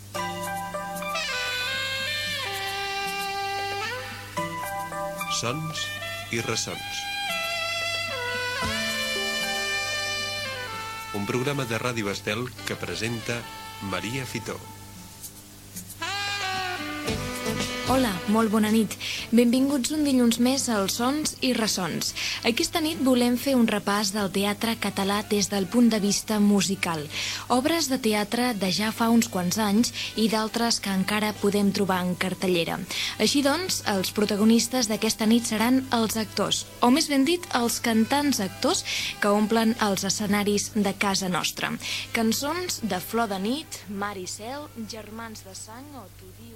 Careta i presentació del programa dedicat al teatre musical
Musical
FM